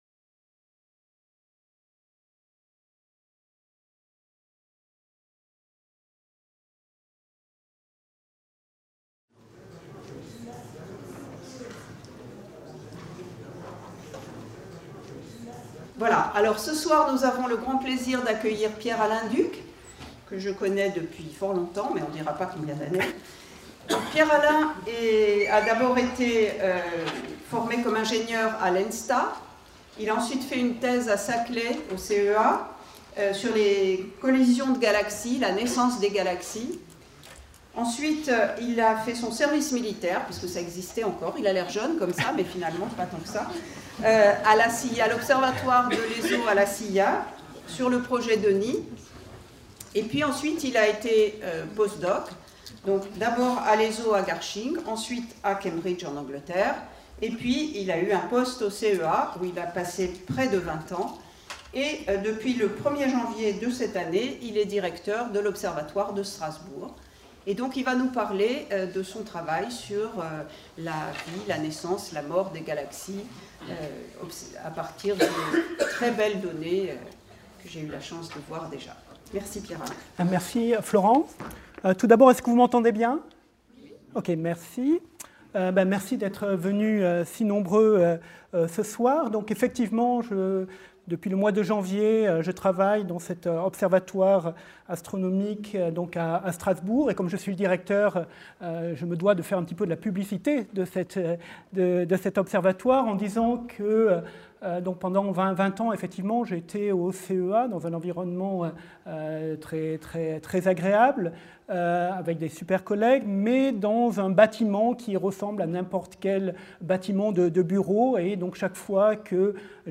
Les images ultra-profondes, multi-longueurs d'onde, et les ambitieuses simulations numériques, produites par les nouvelles générations de télescopes et super-calculateurs sont en train de changer notre compréhension de des regroupements d'étoiles, de gaz et de matière noire. C'est à cet autre regard sur les galaxies que sera consacrée cette conférence.